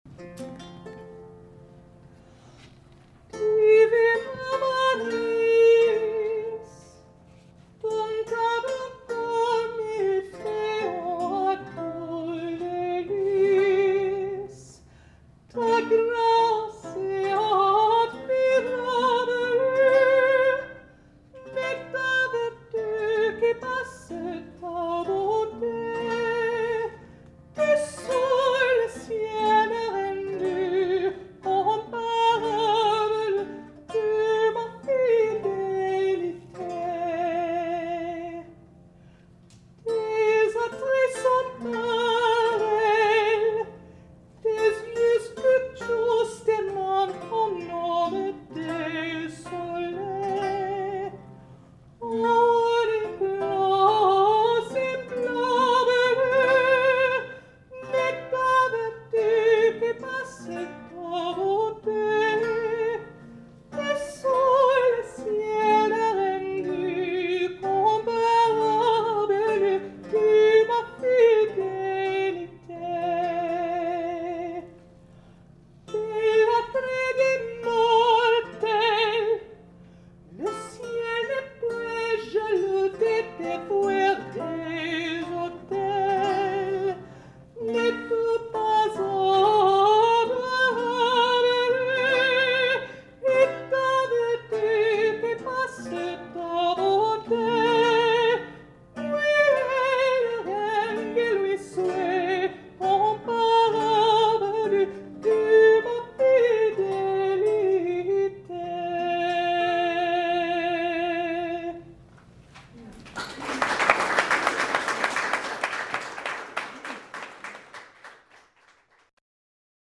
French Courtly Airs of the early 17th Century
A Concert of Early French Song for Lute and Voice – 1603 to 1643
There are thousands of songs that could be described as falling into this category of love songs with these having been arranged for solo voice and lute accompaniment.
The songs are lyrical and flexible, pairing easily the subtle rhythms of the language and catchy melodies that cannot be easily pinned down by any modern concept of meter. Lovers of classical guitar will appreciate the crystal tones of its early cousin the lute, and lovers of art song will appreciate the clarity and warmth of the vocal style.
Air-de-Cour-sample.mp3